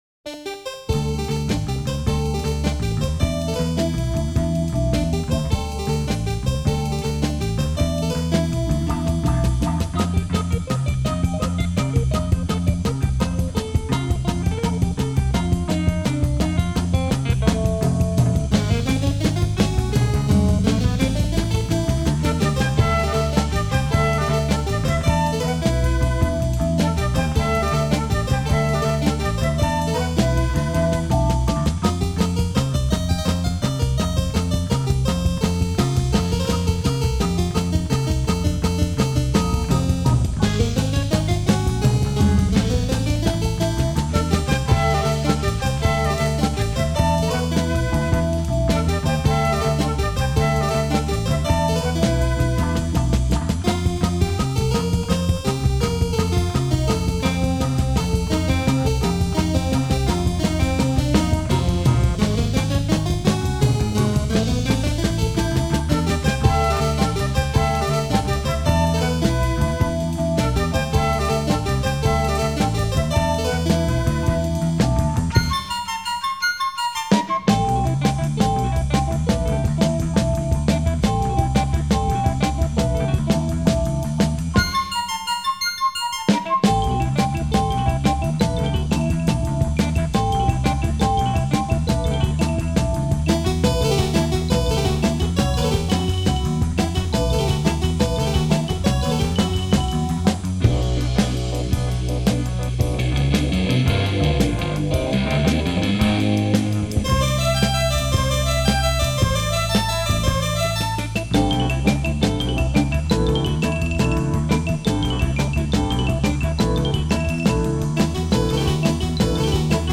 Prog Rock
keyboards and vocals.
bass.
Flute
drums were a remarkable ensemble
Dutch Prog